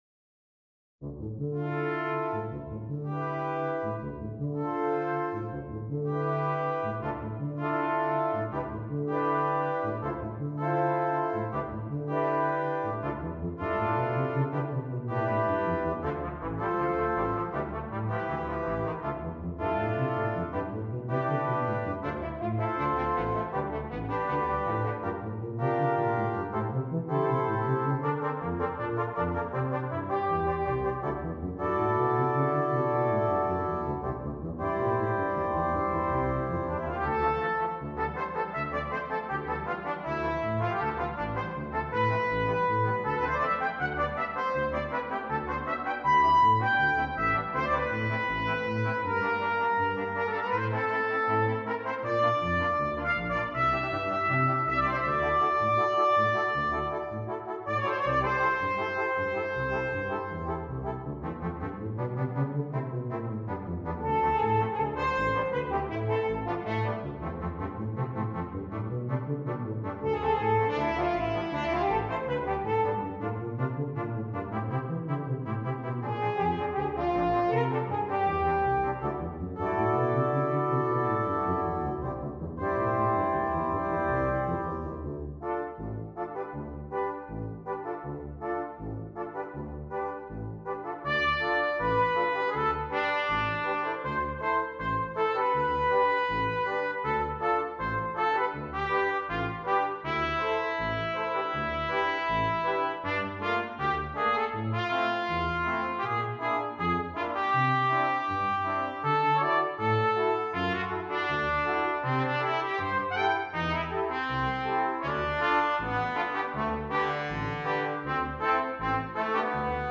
Brass Quintet
featuring colorful writing and a great jazz feel